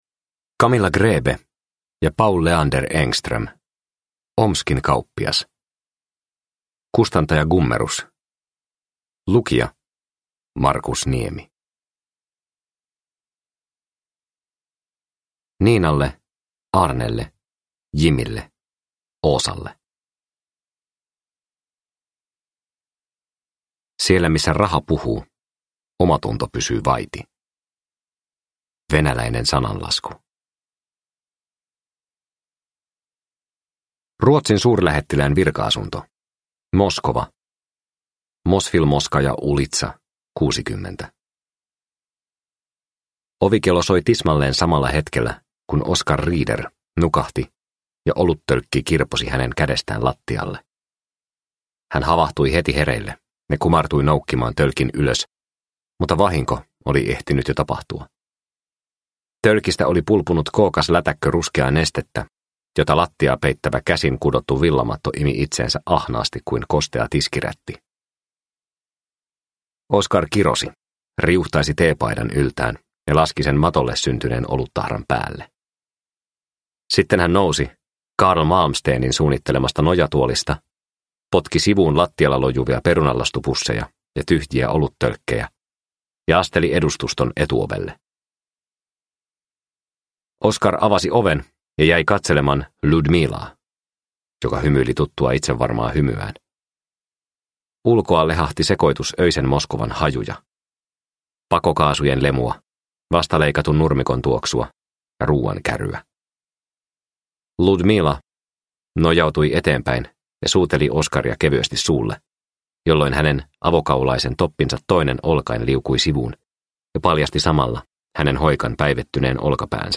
Omskin kauppias – Ljudbok – Laddas ner